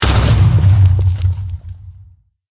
explode1.ogg